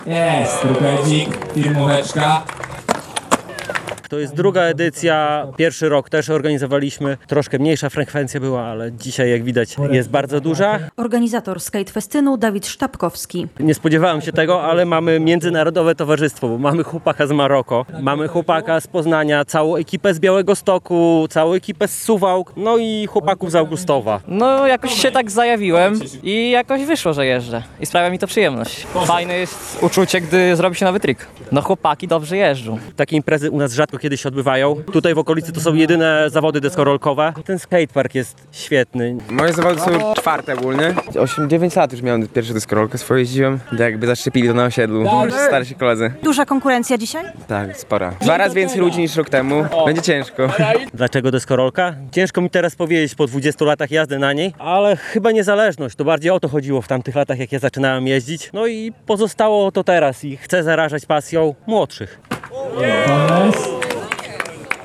II Skate Festyn w Augustowie - relacja